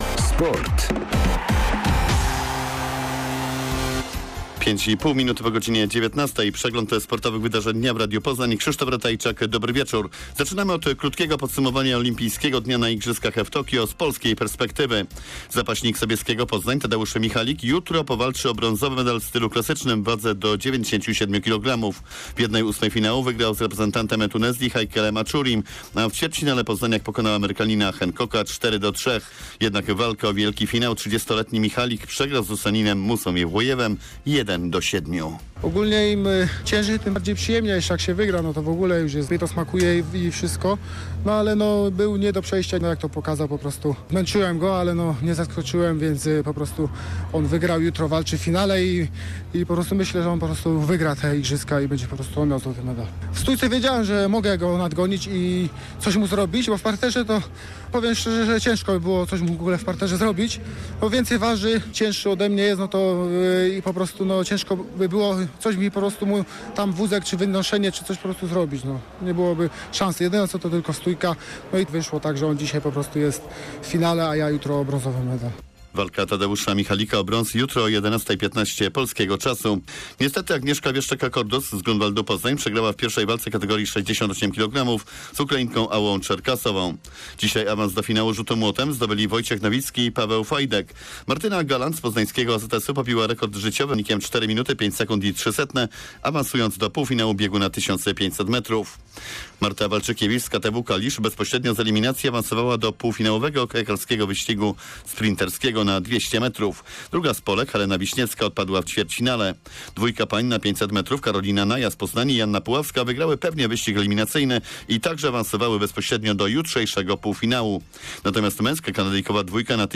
02.08.2021 SERWIS SPORTOWY GODZ. 19:05